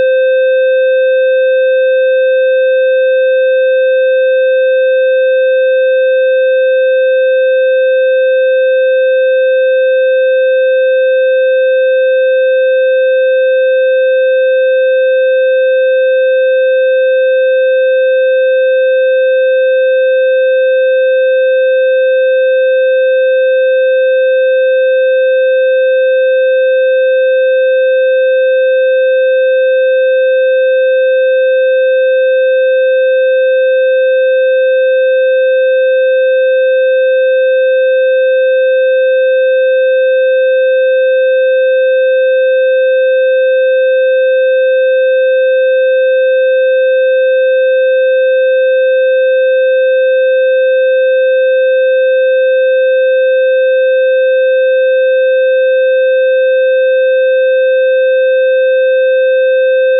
Low Frequency 520HZ Tones: